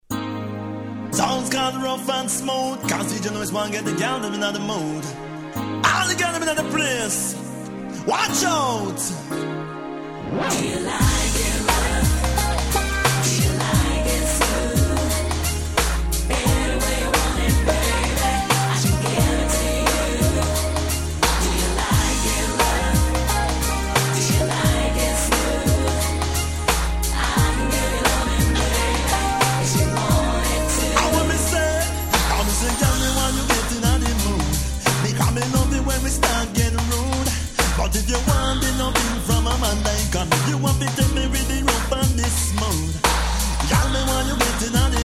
96' Smash Hit Ragga R&B !!
こちらはまったりとした良質Midチューン！